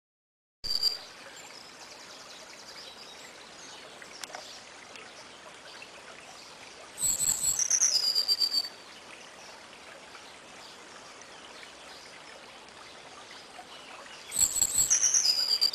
e poi decido di spostarmi nella pineta di Ceri
seguendo il corso di quel fiumiciattolo intravisto dal borgo
ma è soprattutto questo rumore che mi distrae e mi rilassa
il rumore dell'acqua e di un uccello..ma quale?